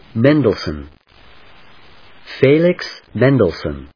音節Men・dels・sohn 発音記号・読み方/méndlsn/, Felix /féɪlɪks/発音を聞く